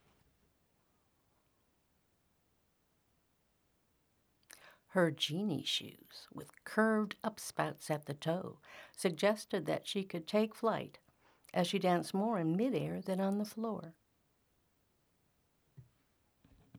At volume setting #7: